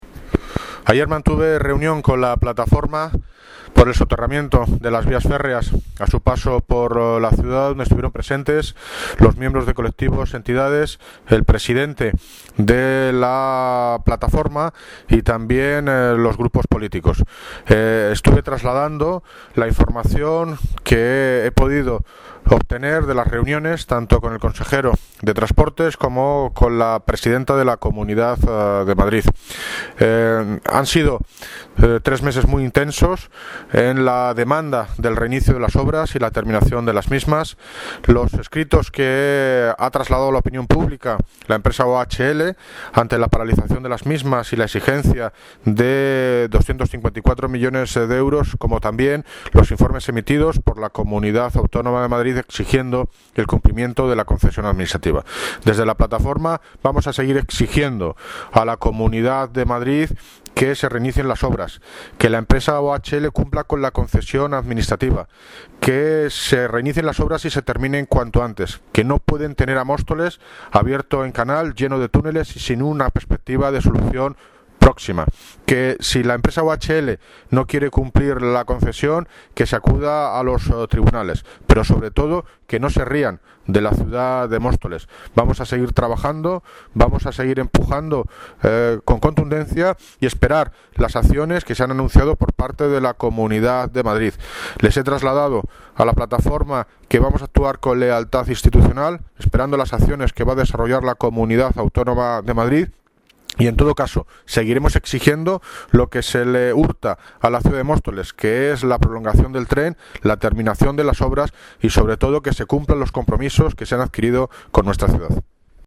Audio - David Lucas (Alcalde de Móstoles) Sobre Reunión con la Plataforma